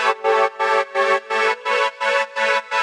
Index of /musicradar/sidechained-samples/170bpm
GnS_Pad-MiscB1:4_170-A.wav